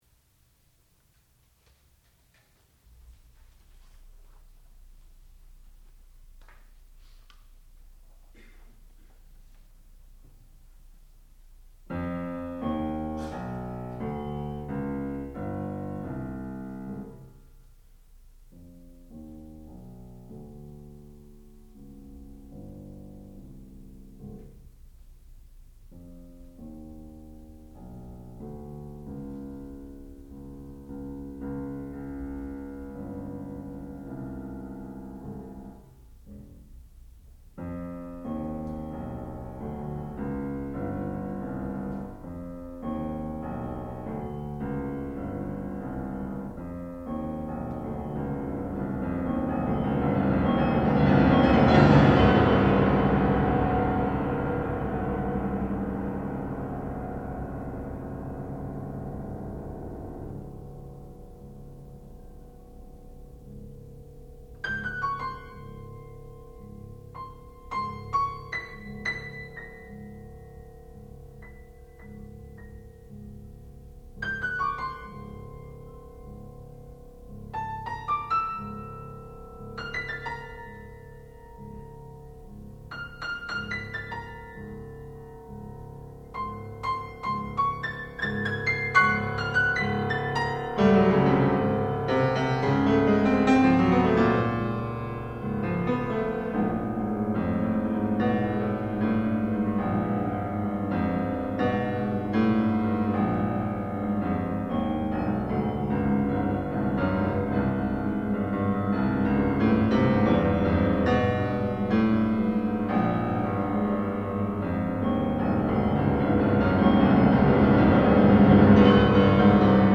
Seven Scenes for Piano and Percussion
sound recording-musical
classical music